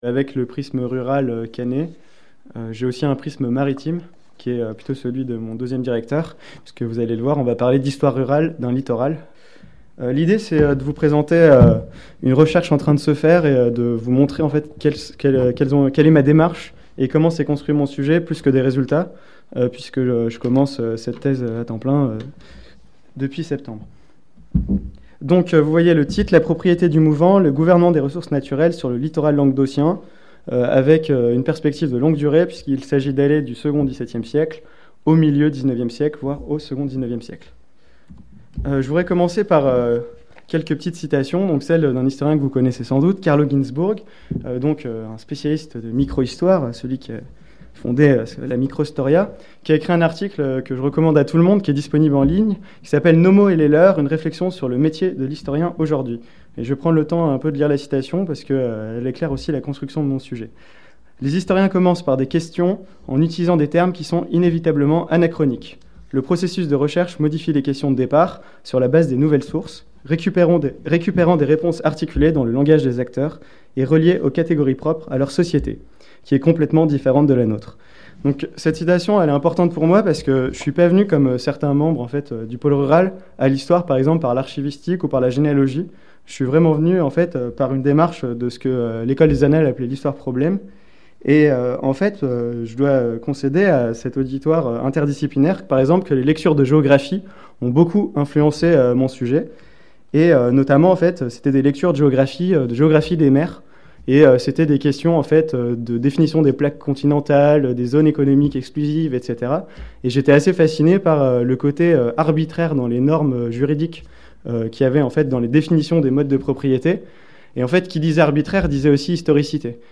Cours/Séminaire
Cette communication a été enregistrée lors d’une séance du pôle rural consacrée à l’état d’avancement des travaux des doctorants du Pôle.